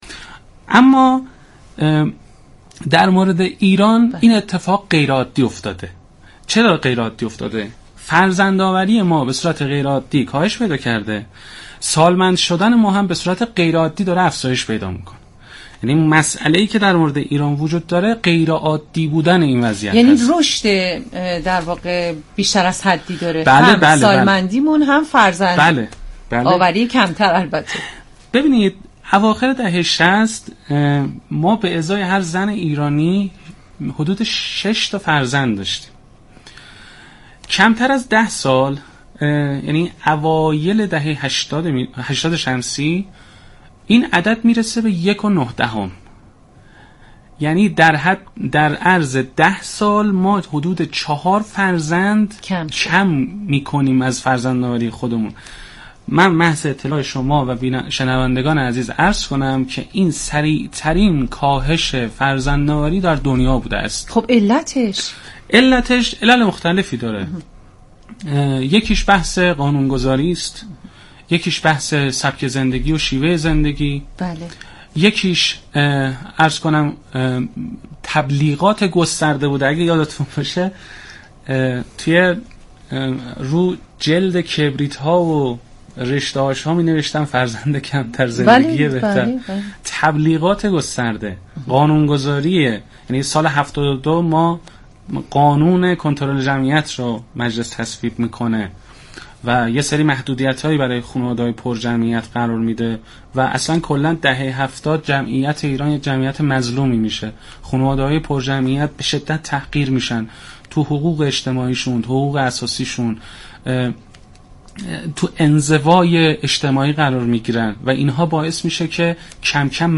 در همین زمینه برنامۀ دال رادیو جوان با حضور كارشناسان حوزۀ جمعیت به بررسی این موضوع پرداخت.